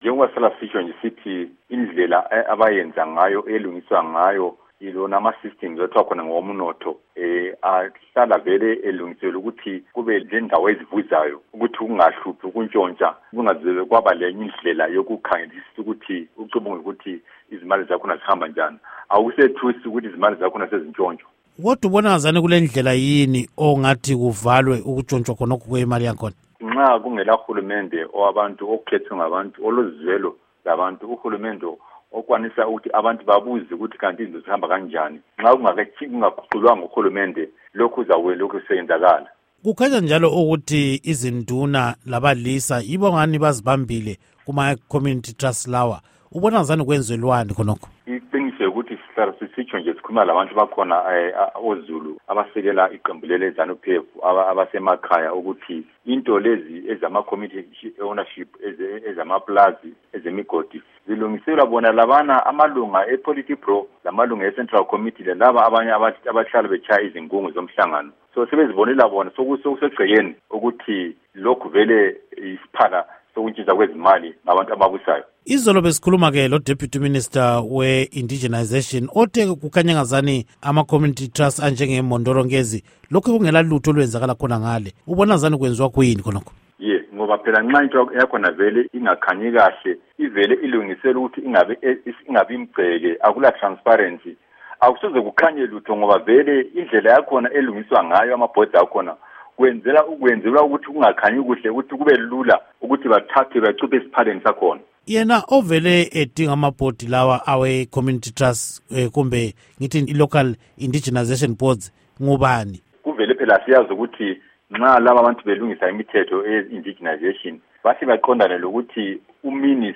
Ingxoxo Esiyenze Lenduna uMtshana Khumalo